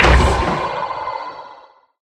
Sound / Minecraft / mob / irongolem / death / hit.ogg
hit.ogg